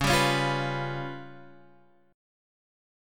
Db7b5 chord